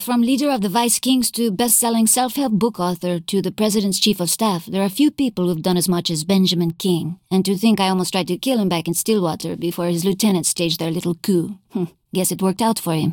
I’m trying to mod some voice files from a game to remove the subtle robotness in the voice.